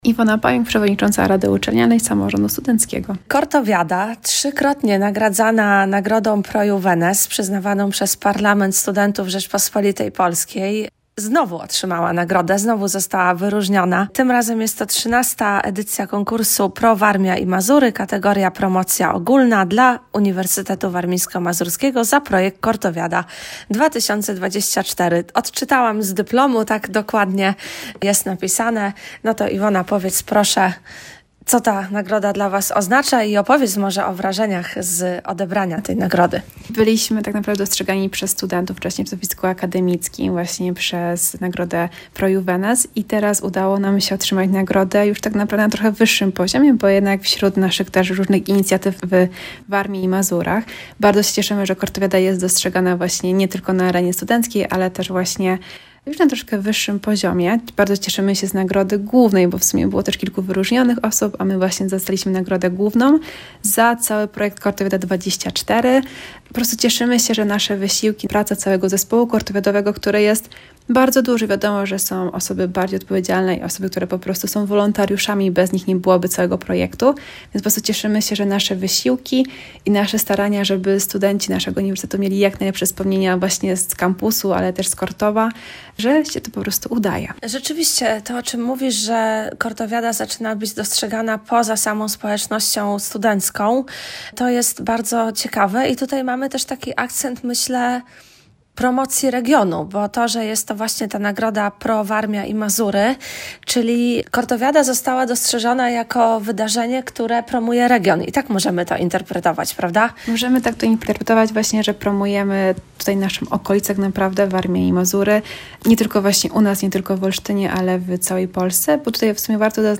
Kortowiada to dobro regionu